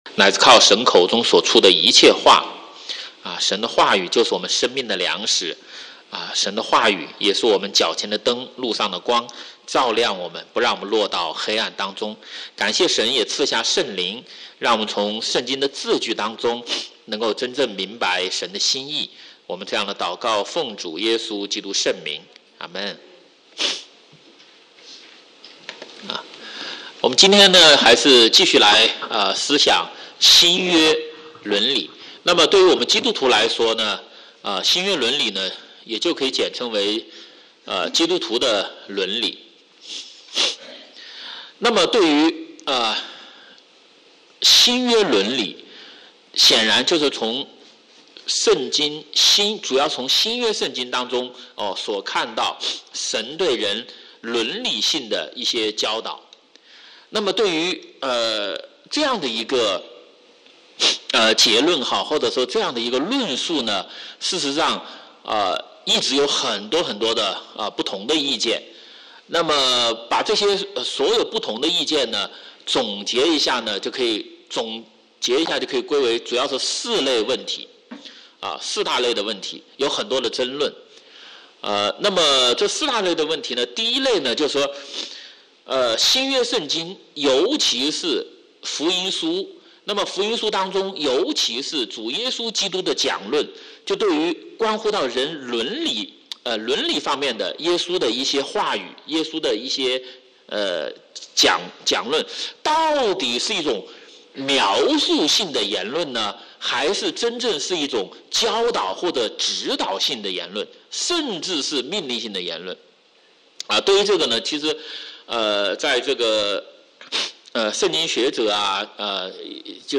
主日學